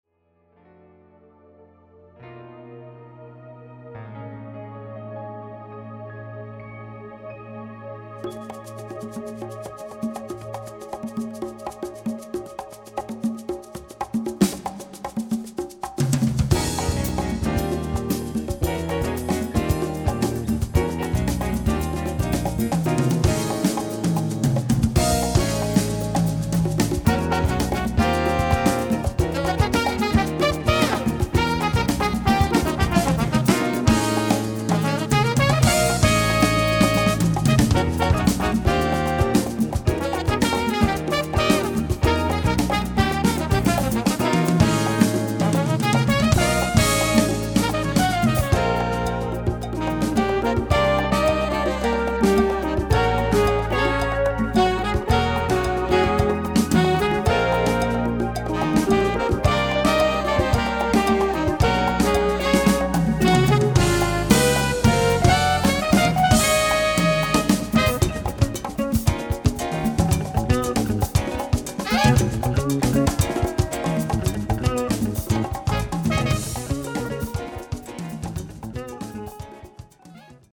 Category: combo (nonet)
Style: mambo